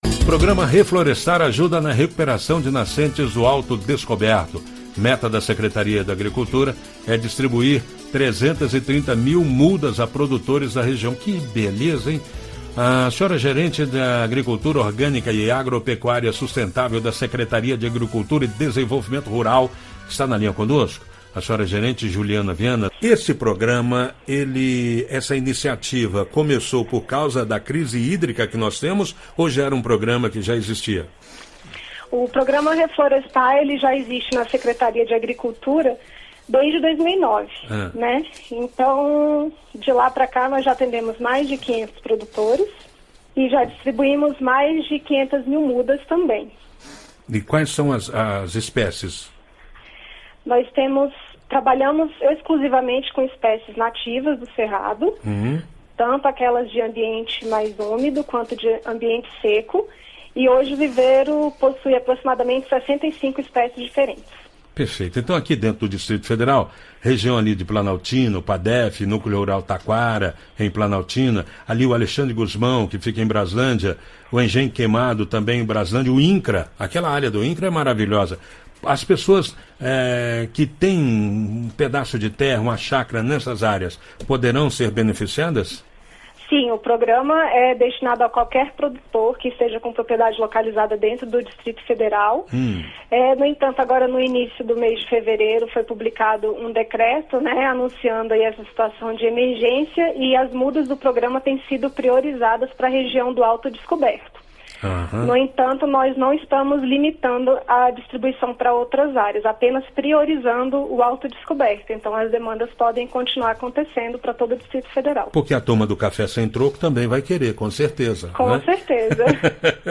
Entrevista: Conheça o programa que ajuda a recuperar nascentes no Alto Descoberto, DF